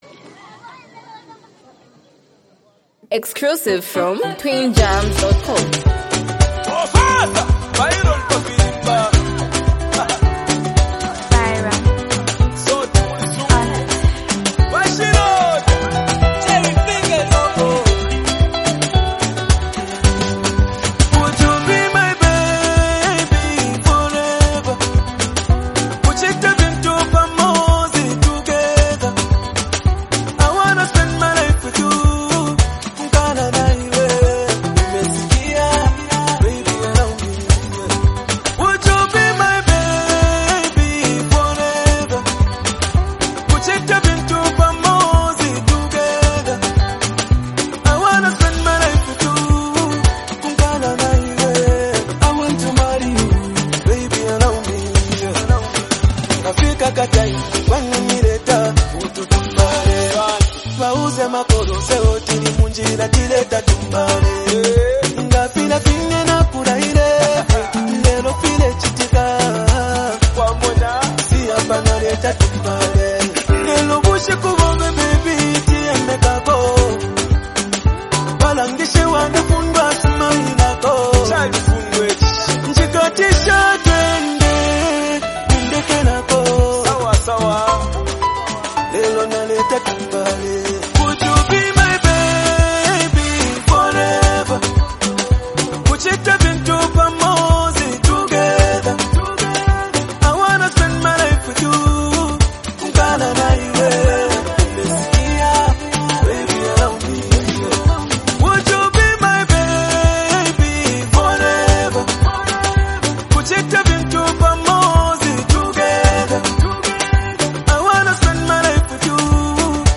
heartfelt love song
soulful vocals